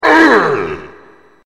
thwomp.mp3